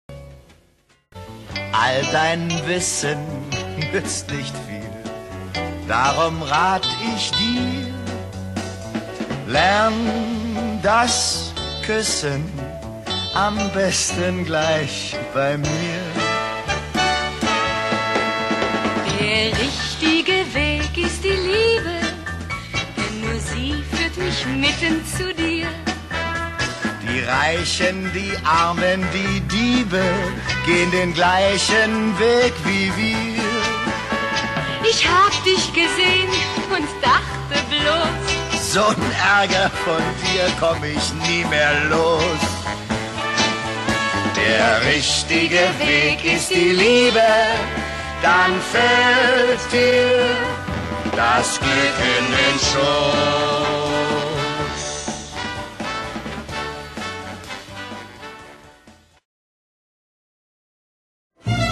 Musical für den Rundfunk (Originalhörspiel)
Besetzung: 9 Darsteller (Sprecher/Sänger)